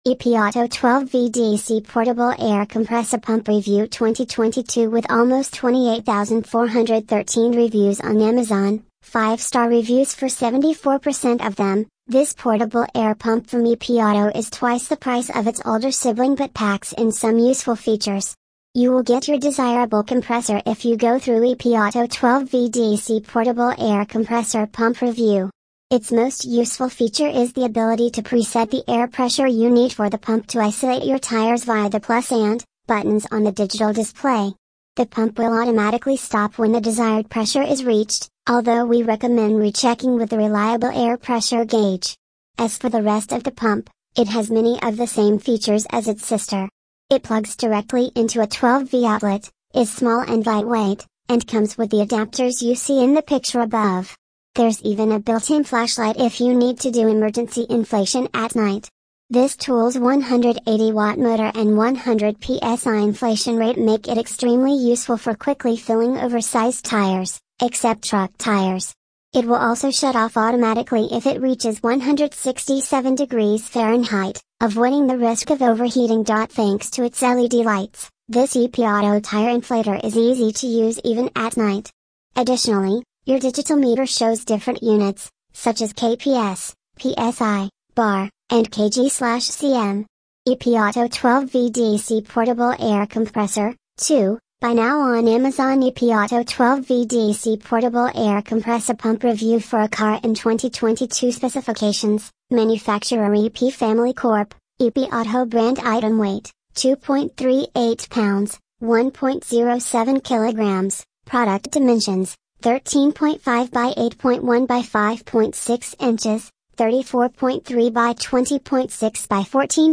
EPAuto 12v DC portable air compressor pump review 2022
Tags: air compressor compressor